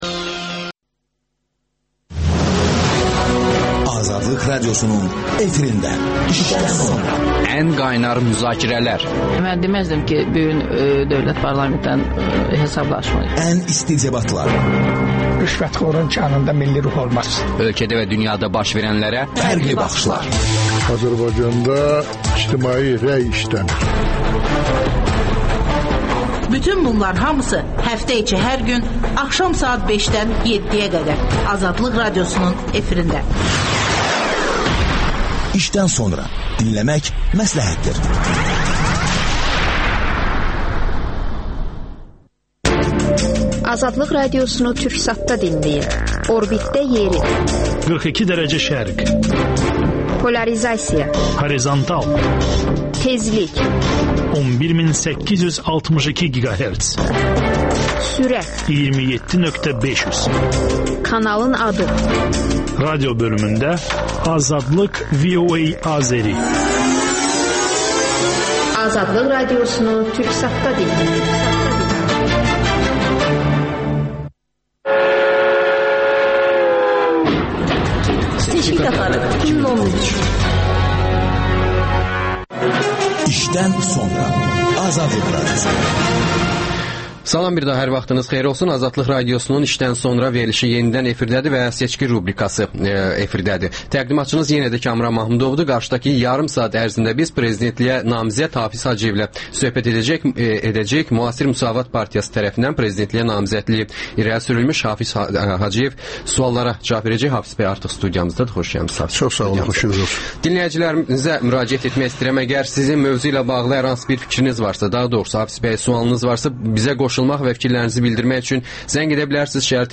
İşdən sonra - Prezidentliyə namizəd Hafiz Hacıyev ilə söhbət